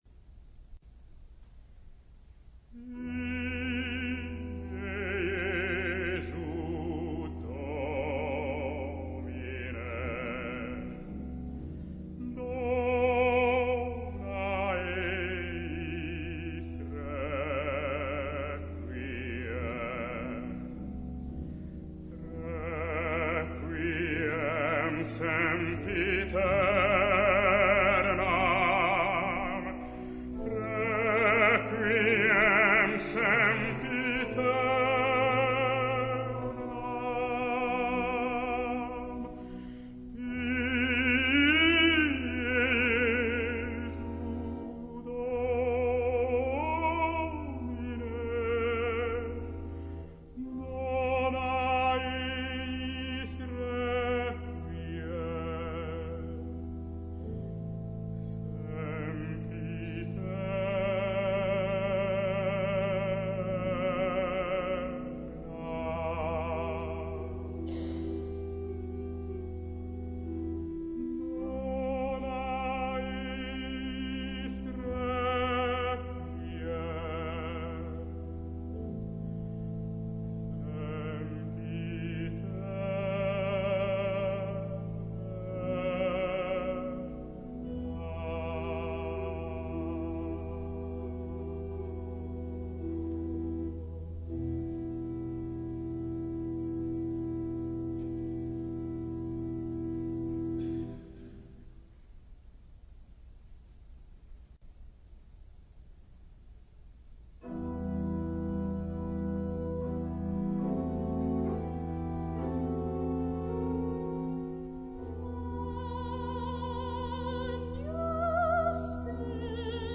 Música vocal rumana